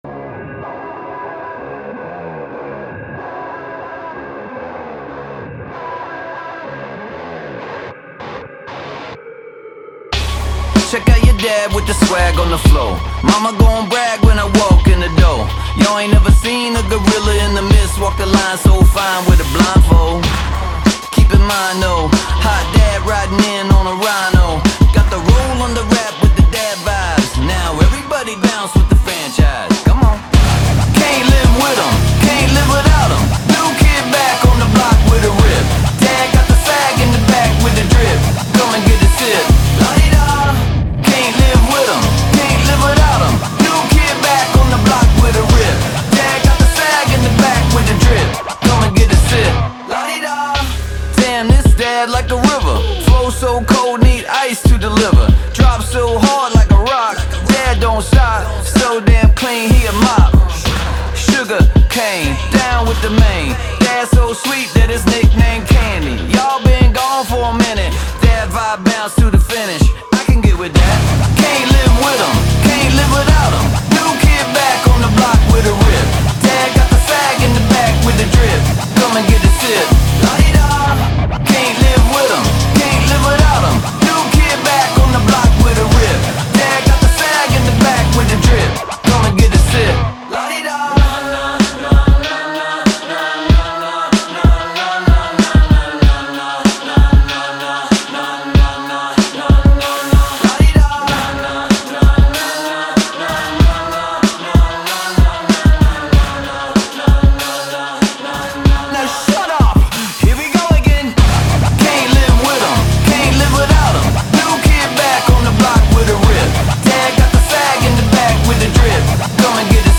BPM95
Audio QualityCut From Video